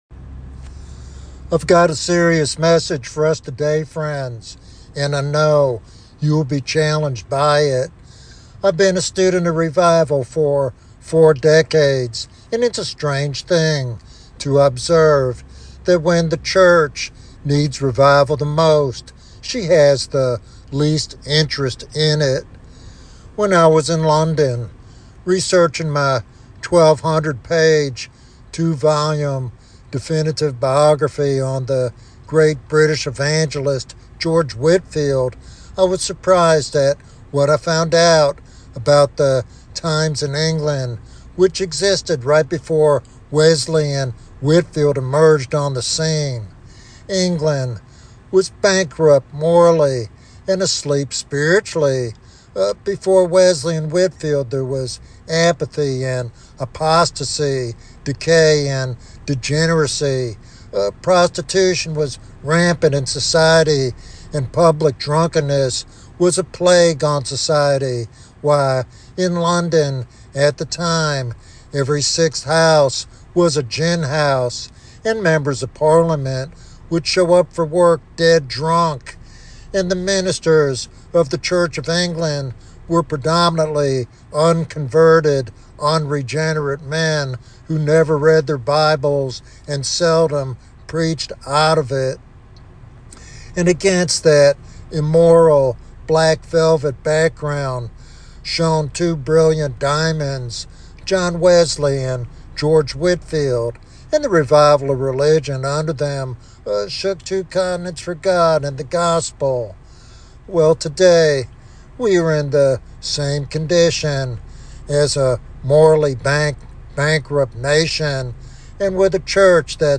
This sermon offers both a sobering diagnosis and a hopeful pathway to spiritual renewal.